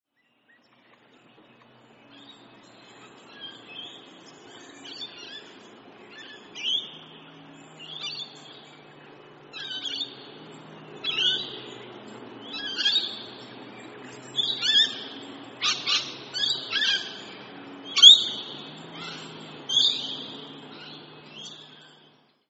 Rainbow Lorikeet - Trichoglossus haematodus
Usually in noisy groups, feeding on nectar, blossoms or fruit.
Voice: shrill screeching, raucous chatter.
Call 1: a group calls in flight
Rainbow_Lori_flight.mp3